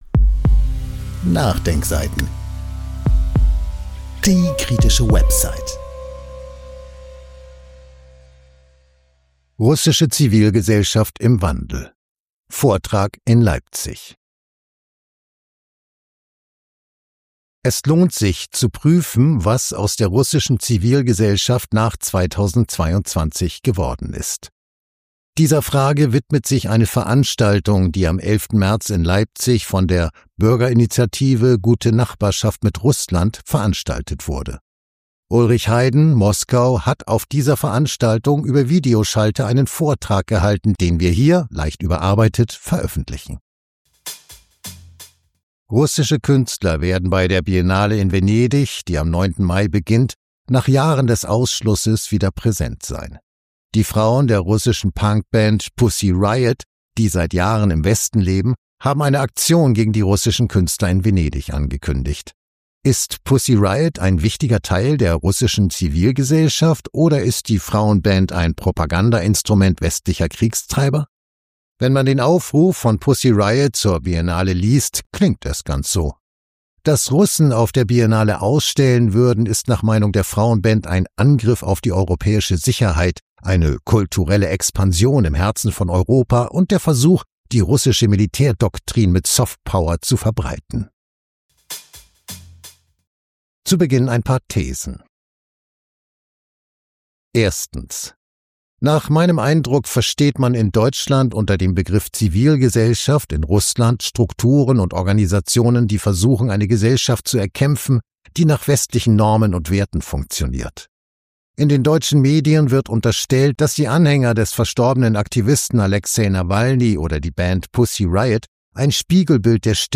Russische Zivilgesellschaft im Wandel – Vortrag in Leipzig